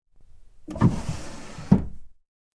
На этой странице собраны уникальные звуки комода: скрипы ящиков, стук дерева, движение механизмов.